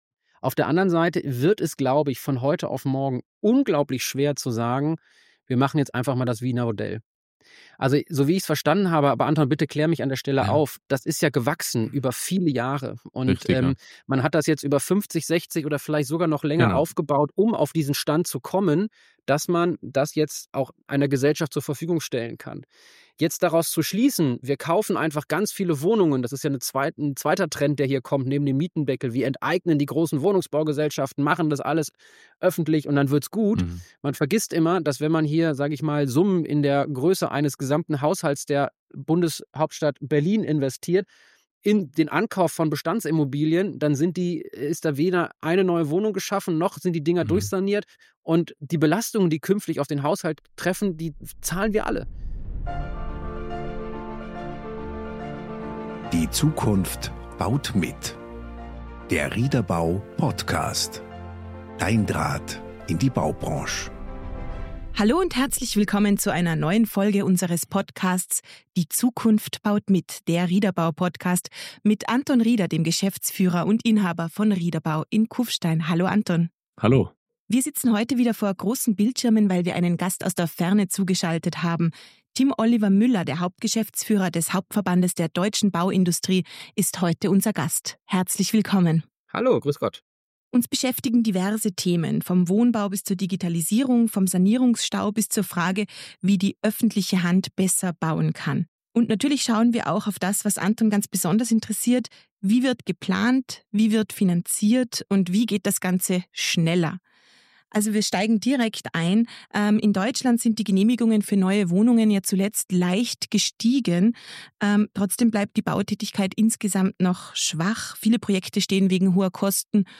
Struktur statt Stillstand – ein Gespräch über die Stellschrauben, die den Bau wieder in Bewegung bringen.